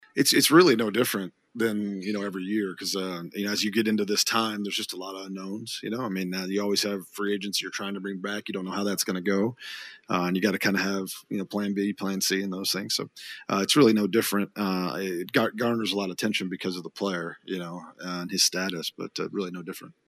GM Brian Gutekunst meets the media in Indianapolis